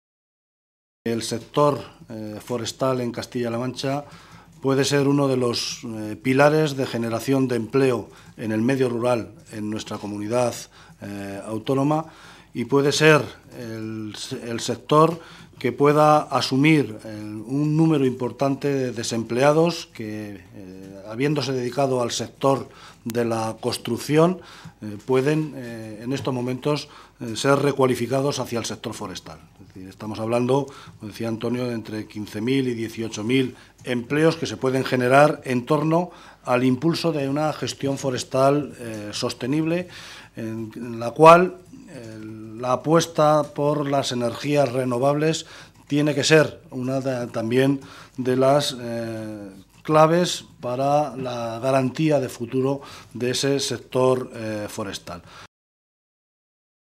Martínez Guijarro realizaba estas declaraciones en la rueda de prensa posterior a la reunión que han mantenido miembros del Grupo socialista con representantes sindicales de este sector de la región.